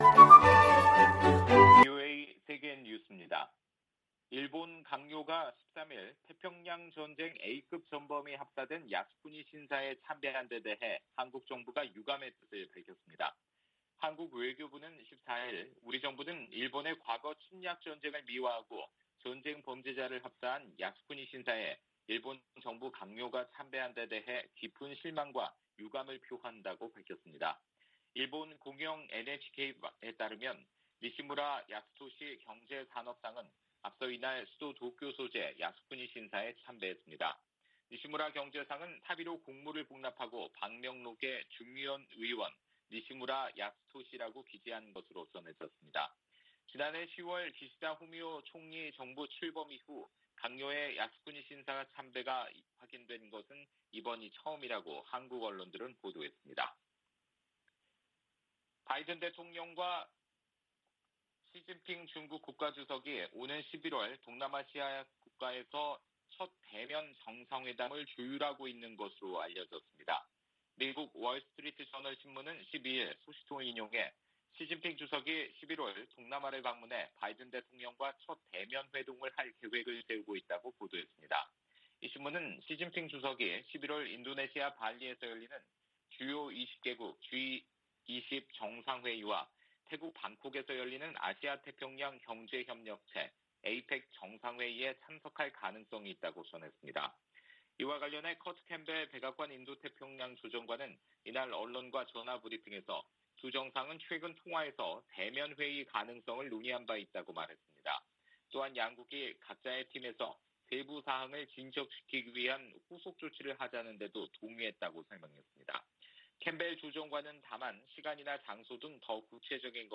VOA 한국어 방송의 일요일 오후 프로그램 2부입니다.